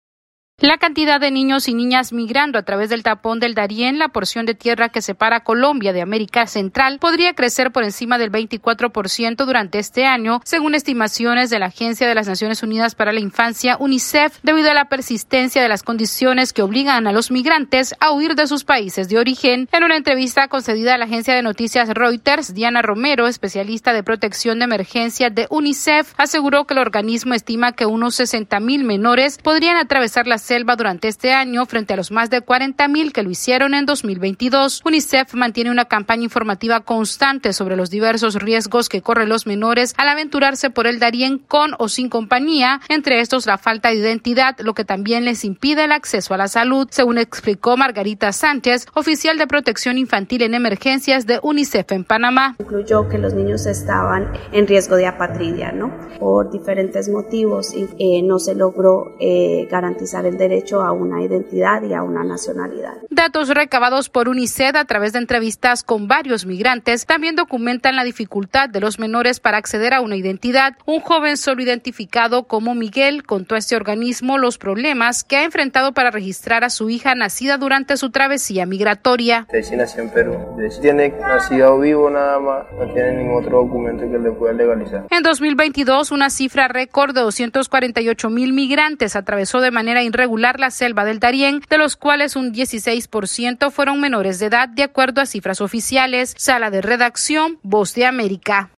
AudioNoticias
Al menos 60 mil menores podrían transitar la peligrosa selva del Darién en Panamá durante el 2023, según las previsiones de UNICEF. Esta es una actualización de nuestra Sala de Redacción...